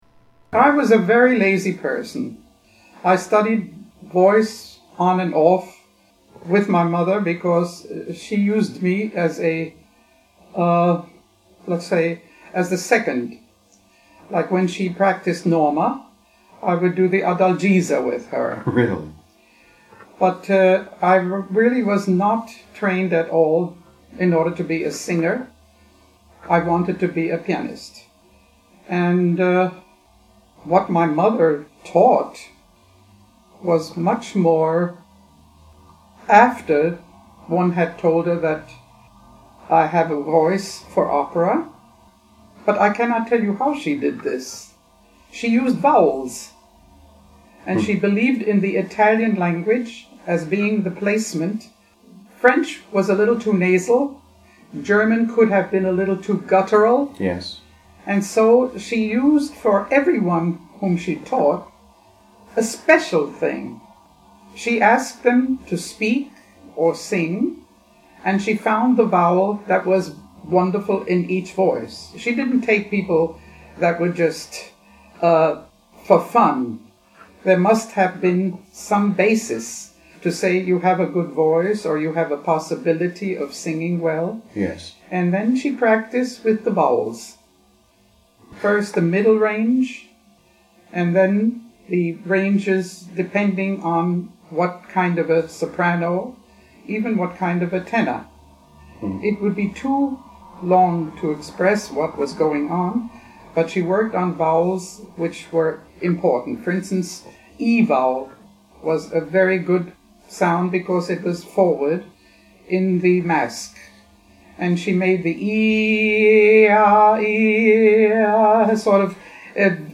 I have included some of her singing and also chose to include two narratives - her letter of introduction to the Met and the saga of saving a Met Götterdämmerung, which are read by me.